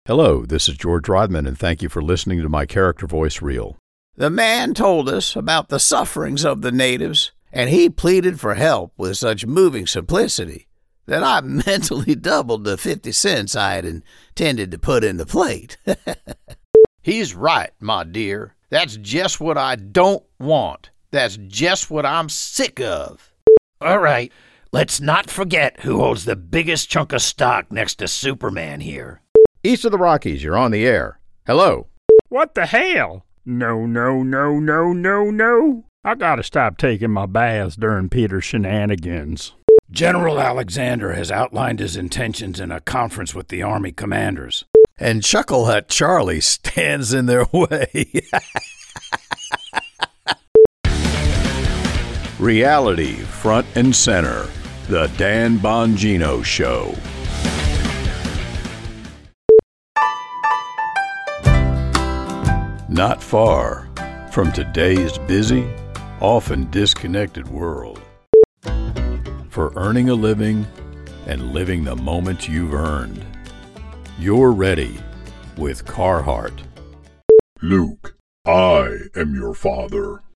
Character Voice Reel
Narrator, Dangerous, Deep, Evil, Explosive, Forceful, Gravelly, Gritty, Informative, Low Pitched, Manly/Masculine, Movie Trailer, Cynical, Ominous, Reassuring, Rugged, Skeptical, Strong, Trustworthy, Voice Of God, Announcer, Blue Collar, Abrupt, Aggressive, Angry, Attractive, Authoritative, Big, Bold, Booming, Bossy, Breathy, Burly, Charismatic, Clear, Compassionate, Condescending, Confident
Middle Aged
My studio is equipped with a RODE 5th Generation NT Mic, a VOLT 1 Audio Interface using Audacity DAW.